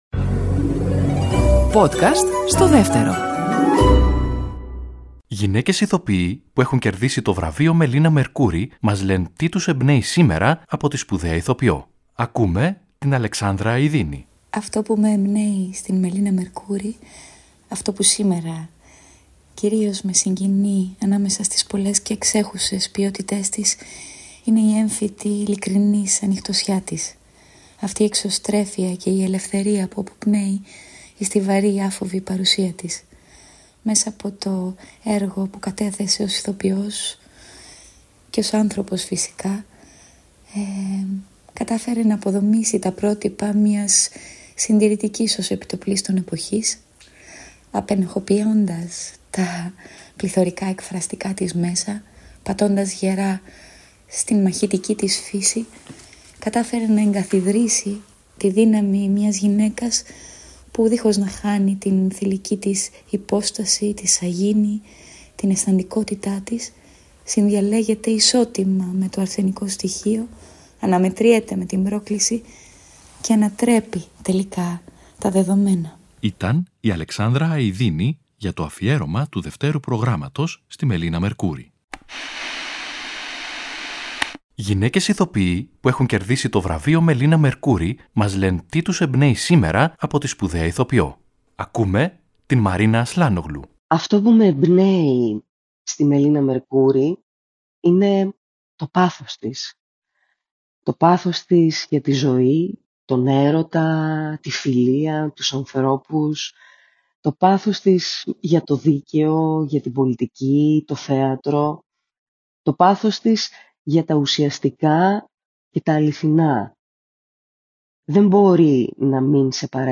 Ακούμε σύγχρονες Ελληνίδες ηθοποιούς που έχουν κερδίσει το Θεατρικό Βραβείο Μελίνα Μερκούρη